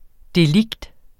delikt substantiv, intetkøn Bøjning -et, -er, -erne Udtale [ deˈligd ] Oprindelse fra latin delictum 'forseelse, overtrædelse', af delinquere 'forse sig' Betydninger 1.